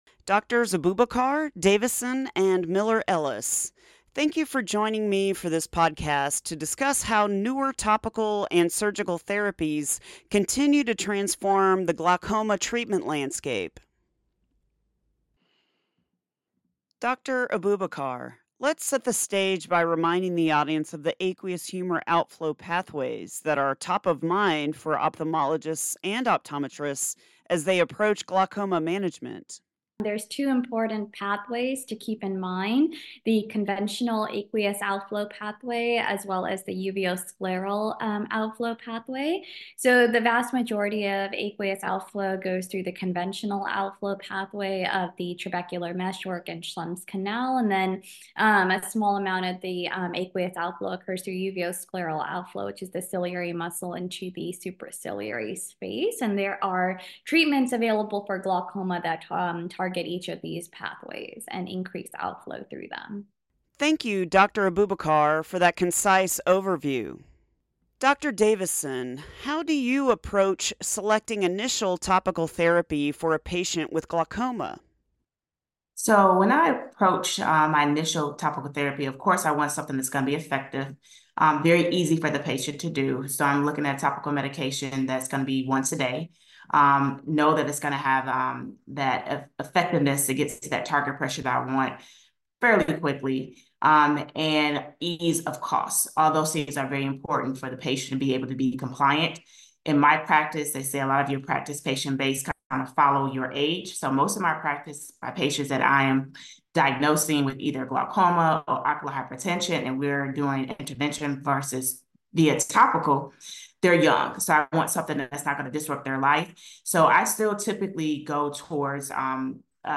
This podcast features engaging commentary from experts in the clinical management of glaucoma. These experts share information about traditional and newer therapies for glaucoma, including topical drops, sustained drug delivery options, and minimally invasive glaucoma surgery. They discuss safety and efficacy data with an emphasis on appropriate patient selection, as well as key take-home points and practice pearls.